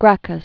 (grăkəs), Tiberius Sempronius c. 163-133 BC.